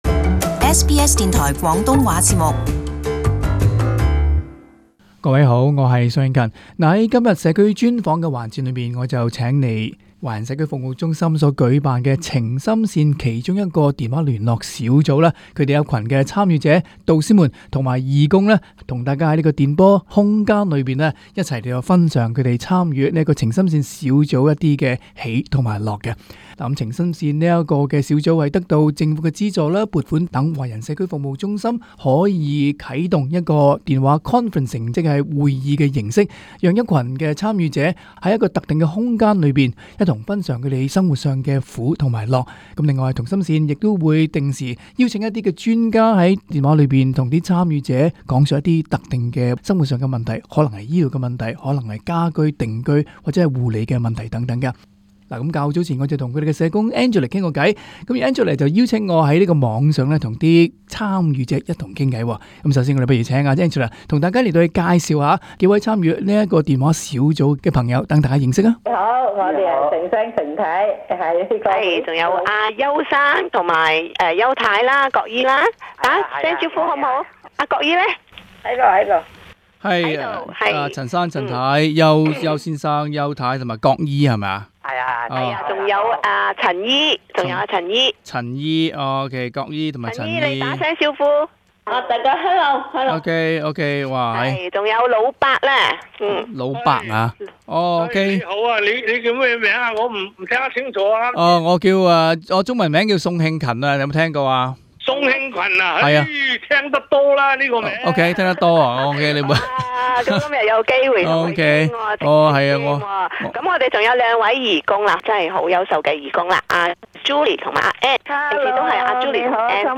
【社区专访】访问华人社区服务中选电话聊天小组-晴心线组员